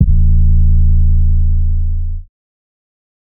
kits/TM88/808s/lex no drop.wav at main